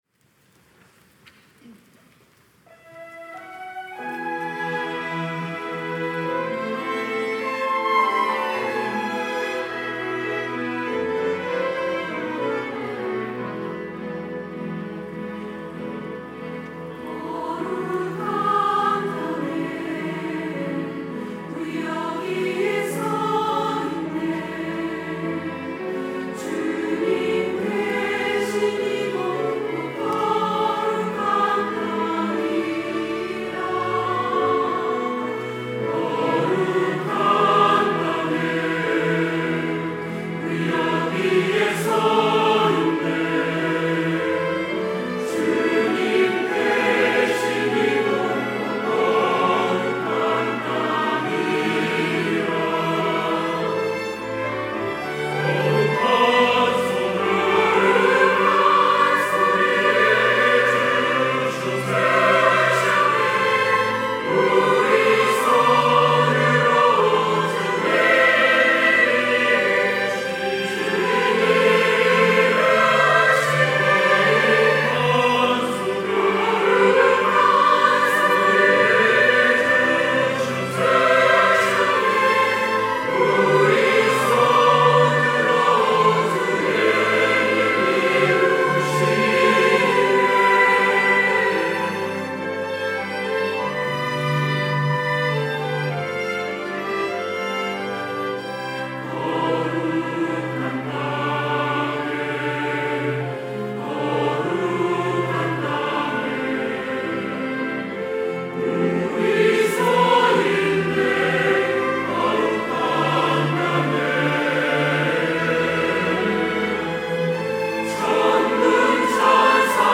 호산나(주일3부) - 거룩한 땅에
찬양대